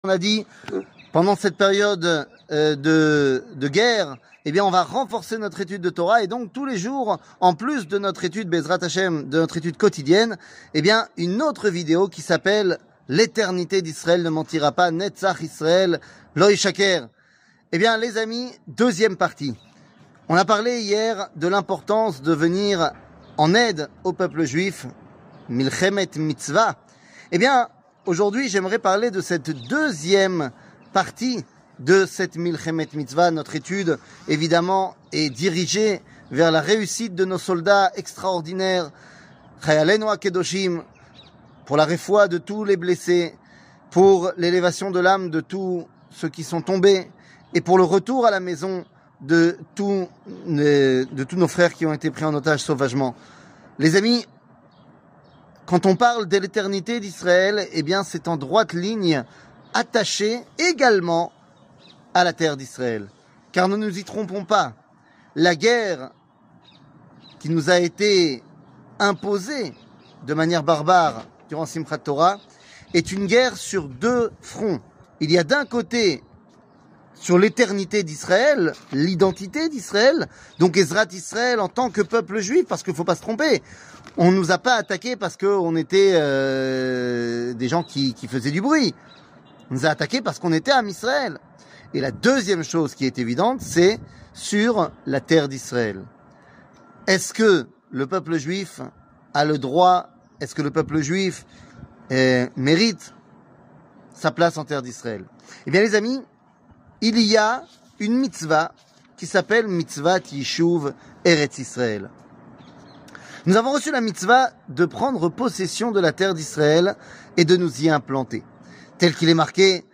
L'eternite d'Israel ne mentira pas ! 2 00:06:33 L'eternite d'Israel ne mentira pas ! 2 שיעור מ 09 אוקטובר 2023 06MIN הורדה בקובץ אודיו MP3 (5.98 Mo) הורדה בקובץ וידאו MP4 (12.88 Mo) TAGS : שיעורים קצרים